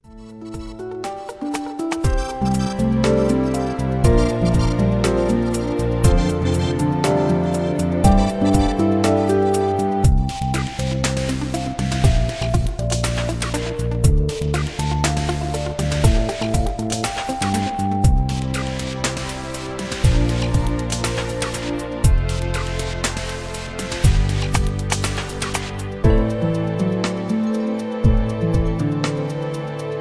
Relaxed Instrumental Ballad